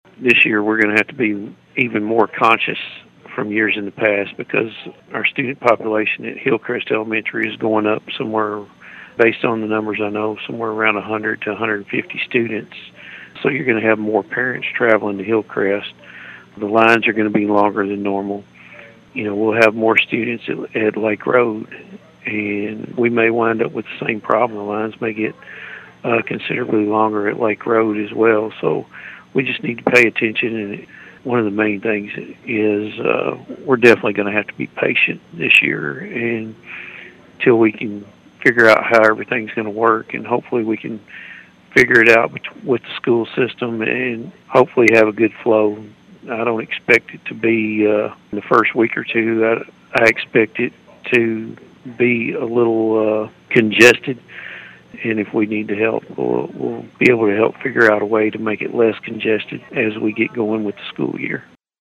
With the closure of Black Oak School, and the consolidation of those students, Sheriff Jackson said traffic control could be an issue to begin the year.(AUDIO)